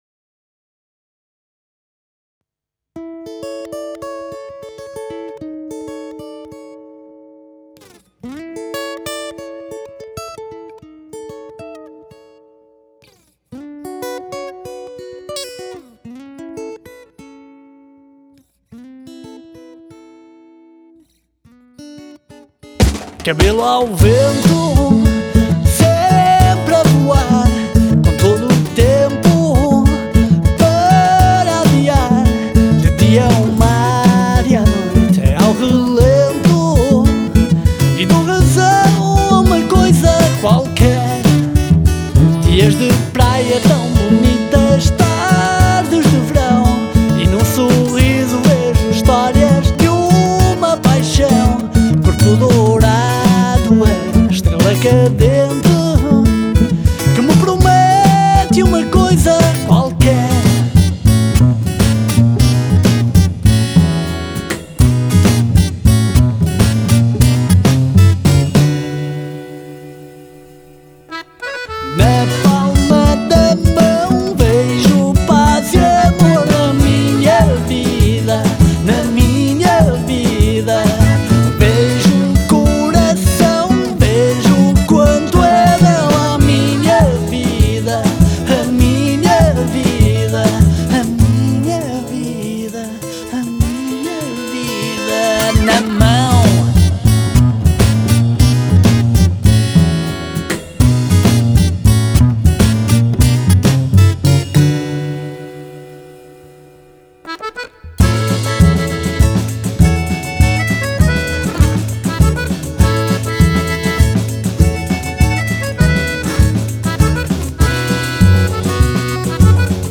cello/voice
percussion
acordeon/chorus
guitar/chorus